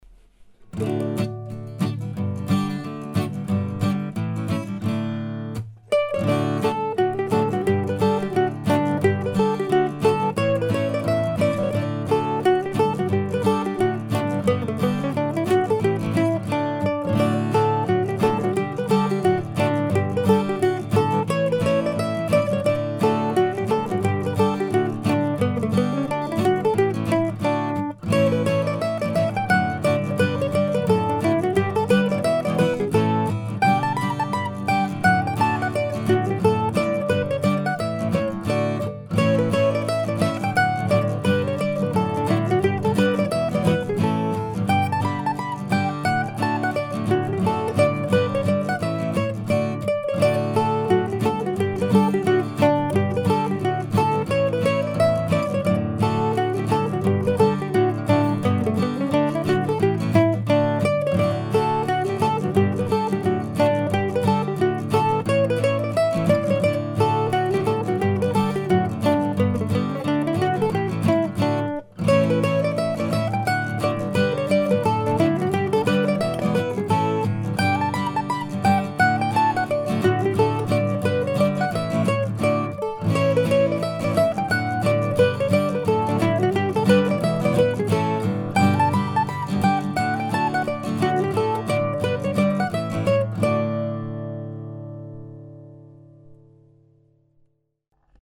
I should have been getting ready for work but this friendly schottische insisted that summer wasn't yet over.